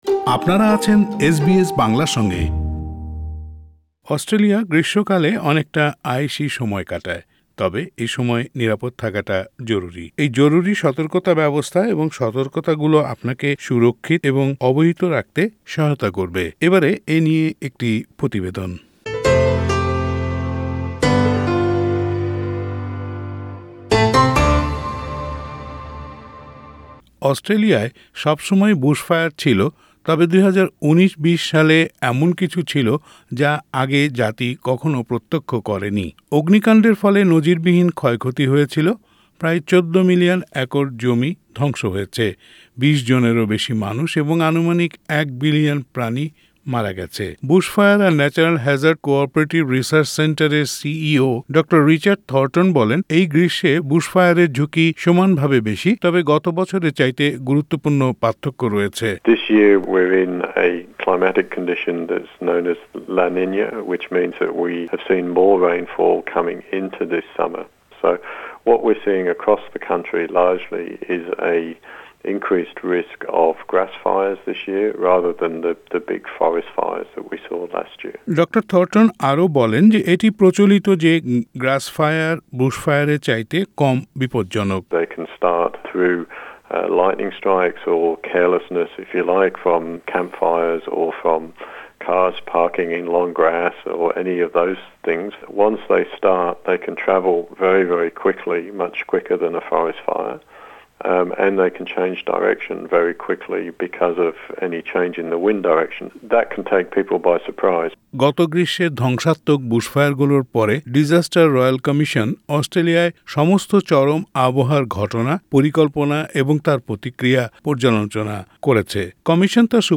এ নিয়ে একটি সেটেলমেন্ট গাইড প্রতিবেদন।